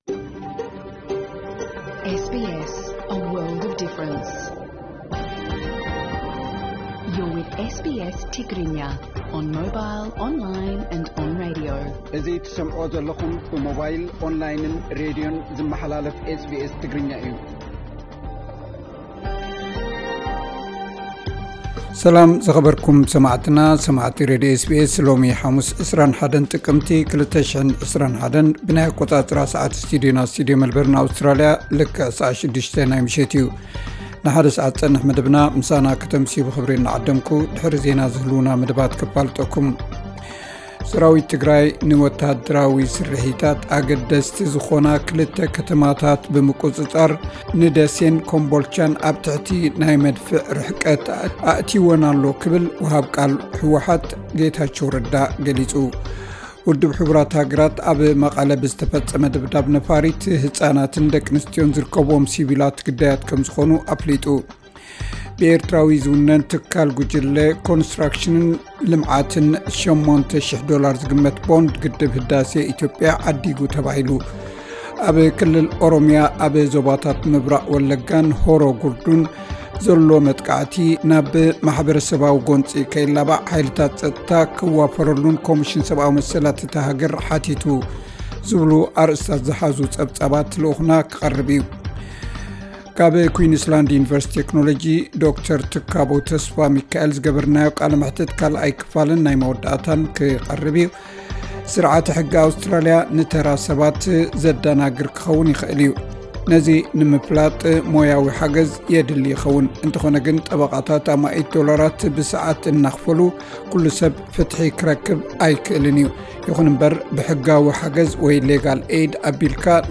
ብሩንዲ ክትከትብ ጀሚራ፡ ኤርትራን ሰሜን ኮርያን ጥራይ ክታበት ኮቪድ ዘይጀመራ ሃገራት ኮይነት። (ካብ ዕለታዊ ዜና)